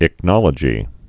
(ĭk-nŏlə-jē)